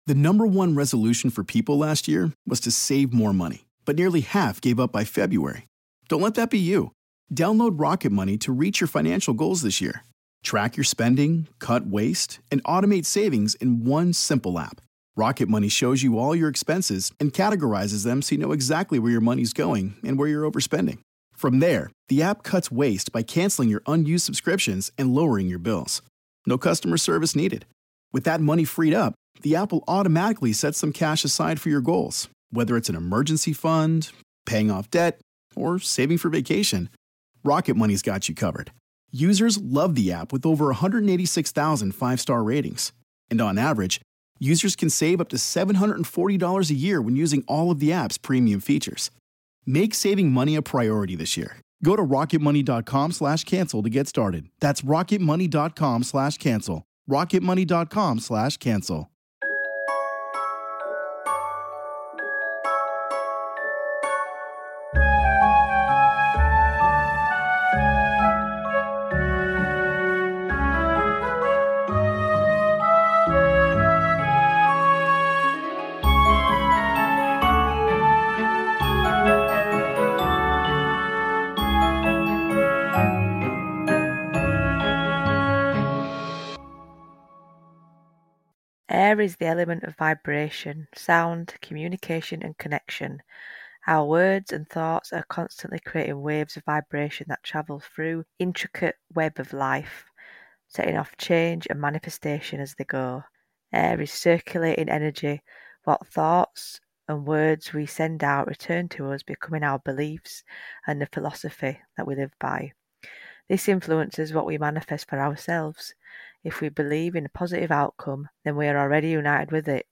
Come sit with us two northern birds and have a laugh as we go off on tangents.
DISCLAIMER: There is swearing and we don't always stay on topic, but everyone loves an unexpected adventure!